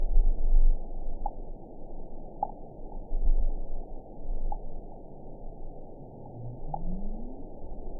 event 912546 date 03/29/22 time 03:41:27 GMT (3 years, 1 month ago) score 8.48 location TSS-AB03 detected by nrw target species NRW annotations +NRW Spectrogram: Frequency (kHz) vs. Time (s) audio not available .wav